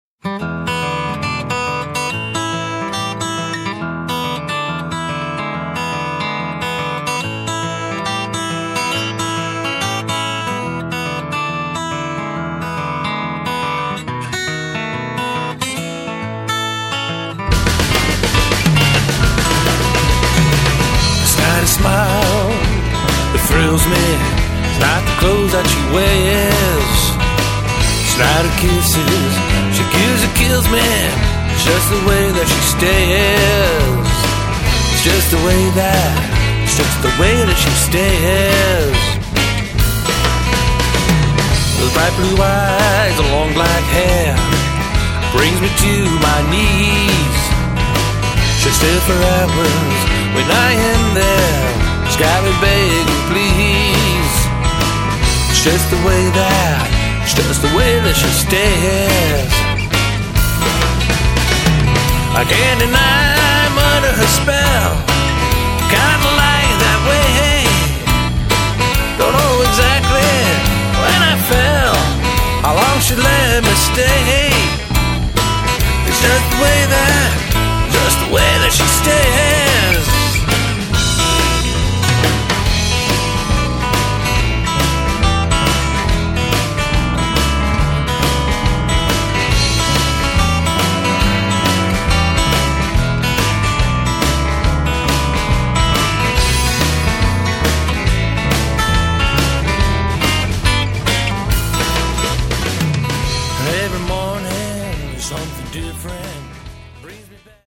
Bluesy, then jazzy, then bluesy again.